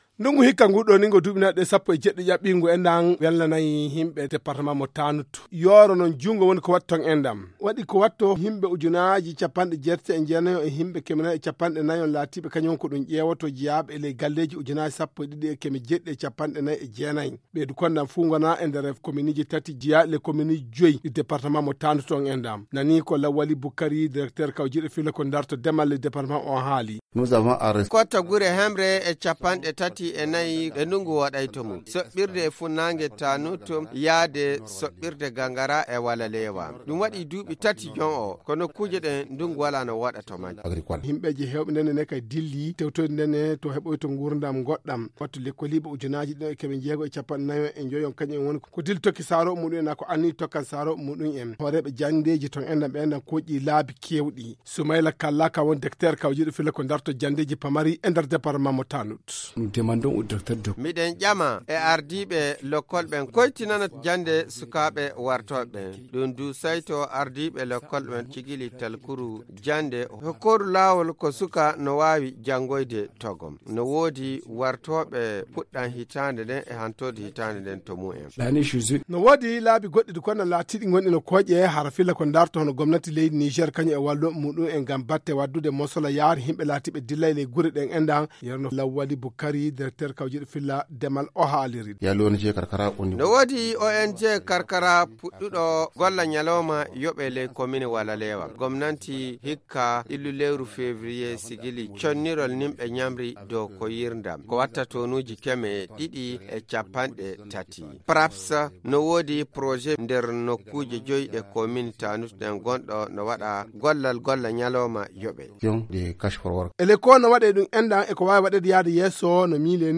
L’évaluation de la campagne agricole a fait ressortir 140 villages déficitaires sur toute l’étendue du département de Tanout où la situation alimentaire est critique en ce moment. Conséquence, dans certains villages, les bras valides sont partis ainsi que des familles entières à la recherche d’un lendemain meilleur. Reportage